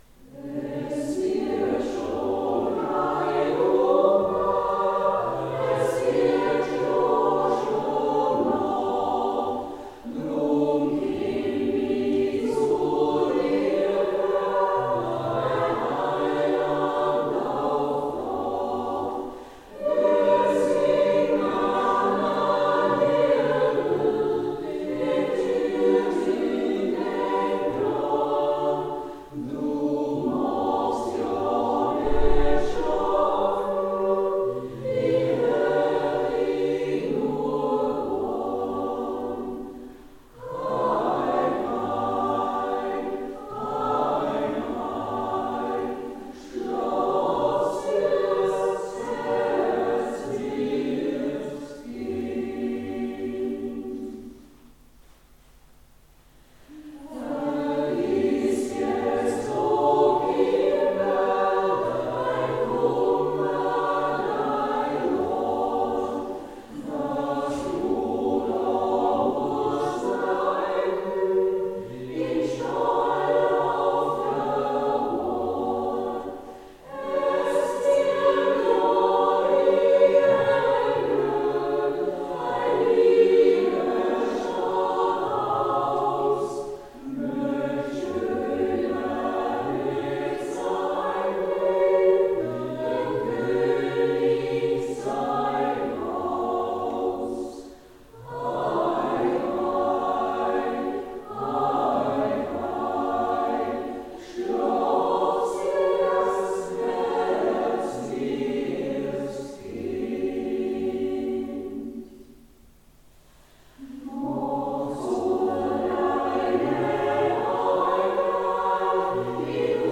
Hier ein Mitschnitt vom Adventkonzert 2022 in der Pfarrkirche Thal vom 28.11.2022